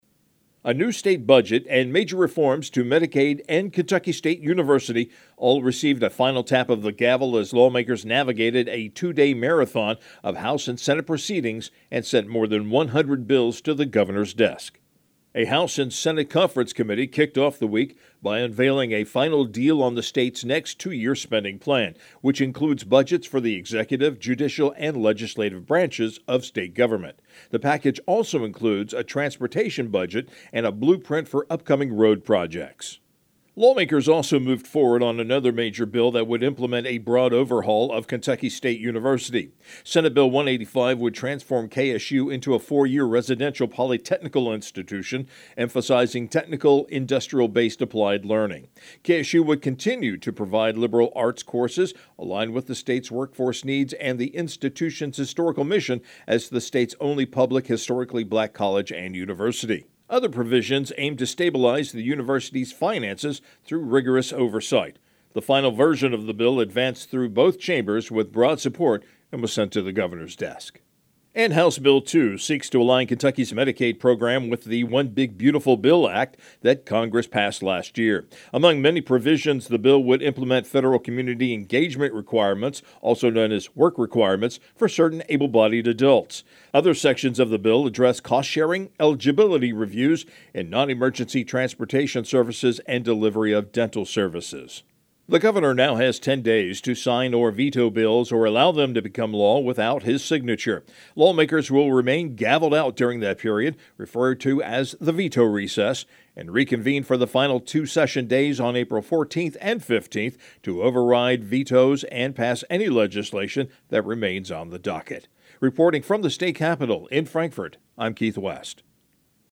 LRC Public Information offers audio feeds over the Internet to help radio news directors obtain timely actualities and pre-produced news spots.